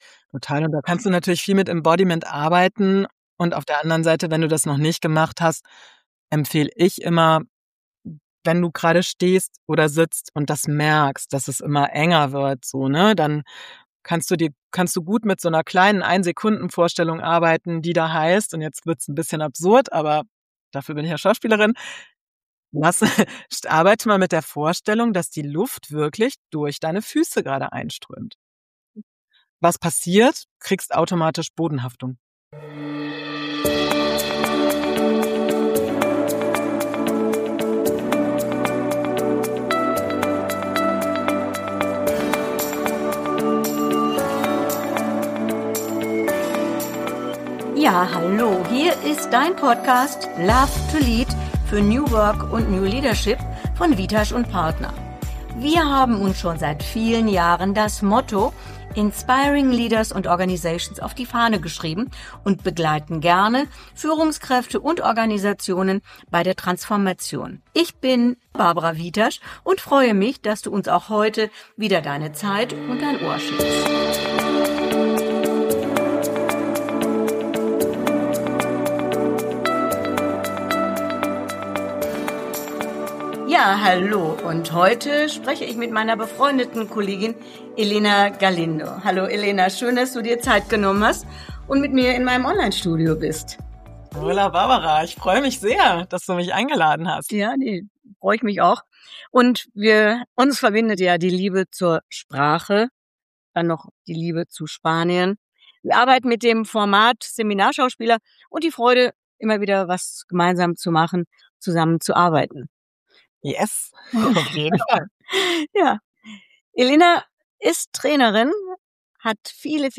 Ein Gespräch über Resonanz, Authentizität und die Freude daran, Menschen einen ehrlichen Spiegel vorzuhalten – manchmal so intensiv, dass Teilnehmende am Ende sogar gemeinsam singen.